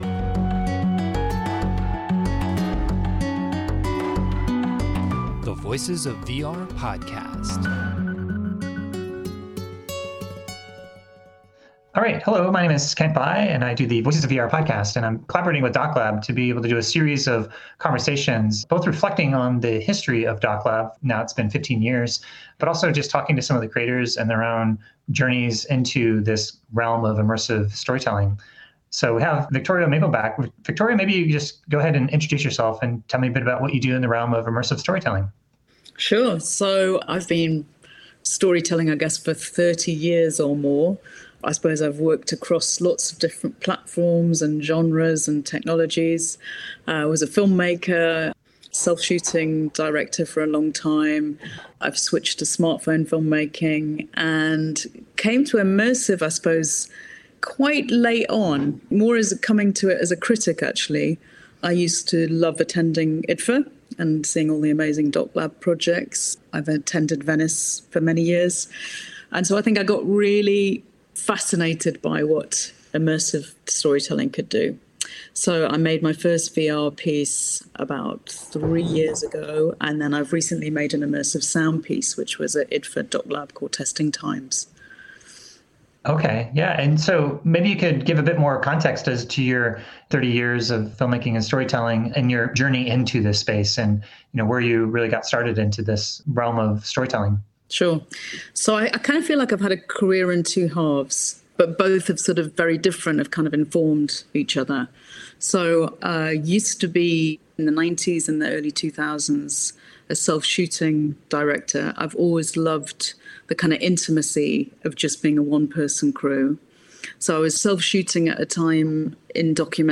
This was recorded on Friday, December 3, 2021 as a part of a collaboration with IDFA’s DocLab to celebrate their 15th year anniversary.